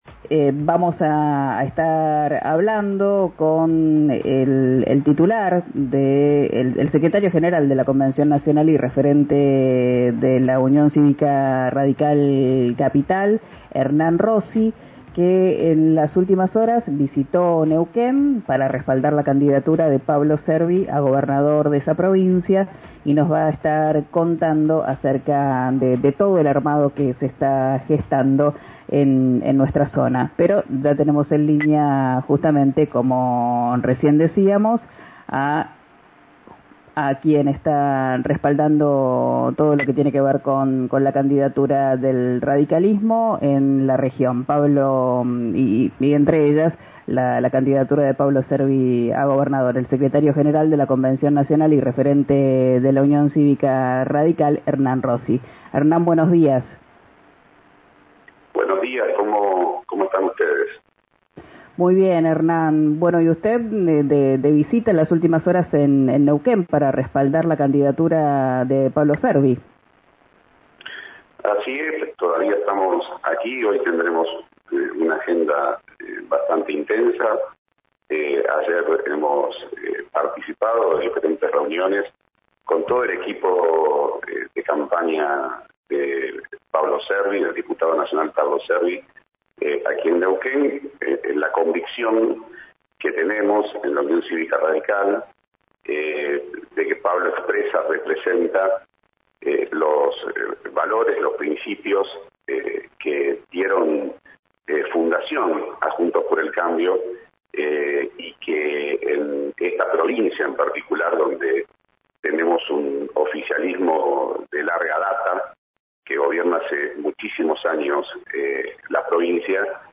En diálogo con «Quién dijo verano», por RÍO NEGRO RADIO